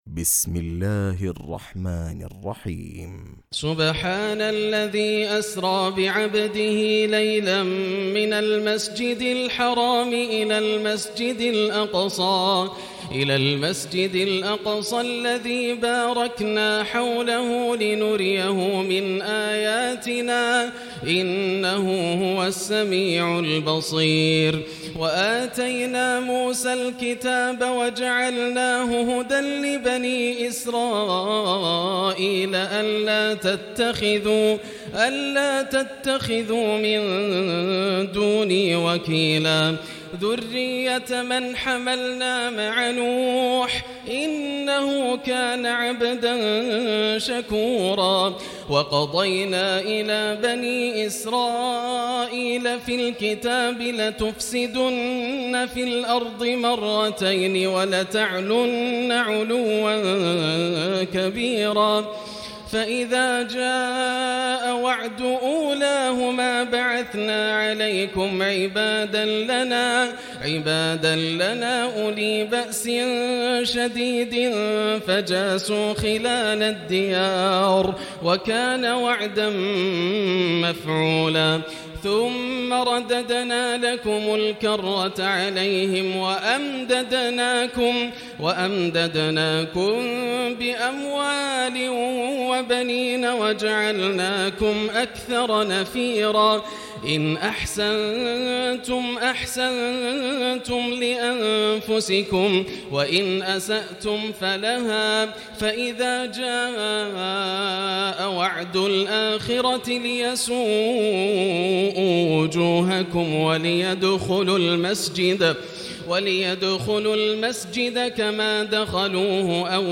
تراويح الليلة الرابعة عشر رمضان 1439هـ من سورة الإسراء (1-100) Taraweeh 14 st night Ramadan 1439H from Surah Al-Israa > تراويح الحرم المكي عام 1439 🕋 > التراويح - تلاوات الحرمين